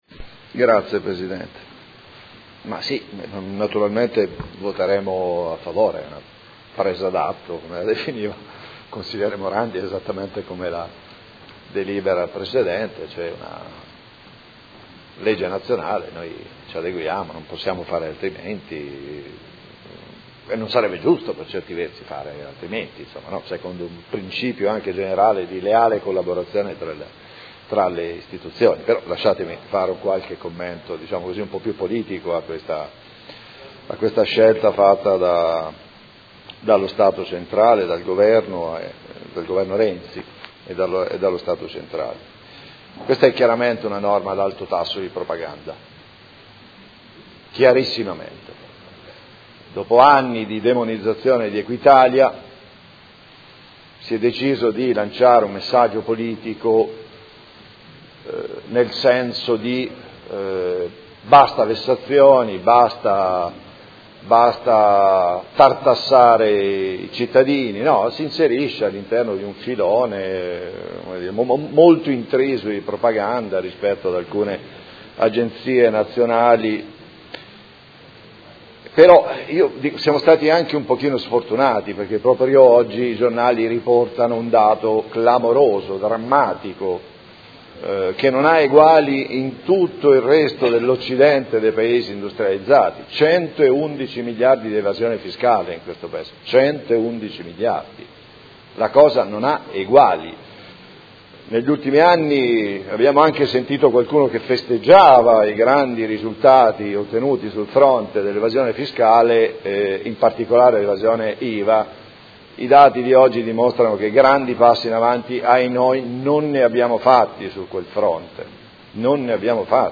Seduta del 30/03/2017. Dichiarazione di voto su proposta di deliberazione: Affidamento della riscossione coattiva delle entrate comunali, tributarie e patrimoniali, all’Ente nazionale della riscossione, Agenzia delle Entrate